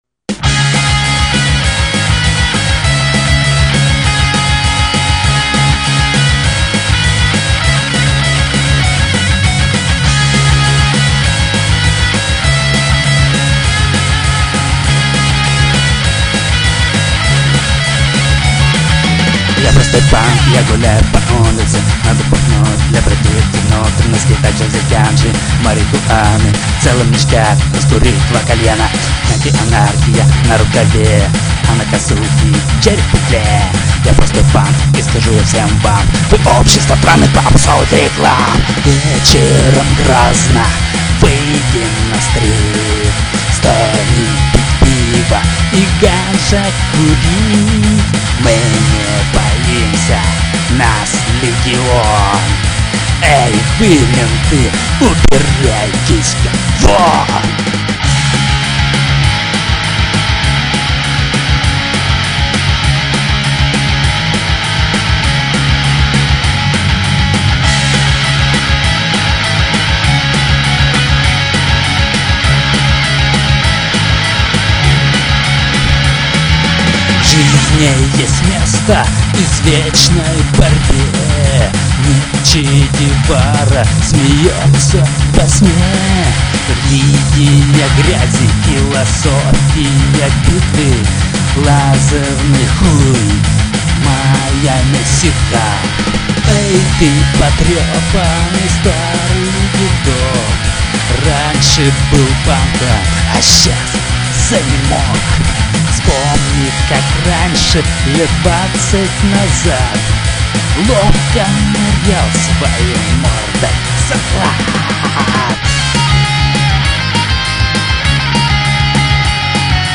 6. микрофон дишовый кондесаторный для разговора с компом -(другого нихуя не нашлось) 7. медиатор fender толщины 1,14 мм заиметь пестню паннк, грузоподъемностью 954 килобайта качества 44 кб/с и моно, не заплатив денекк, короче, безвоздмездно пока, тут
punk.mp3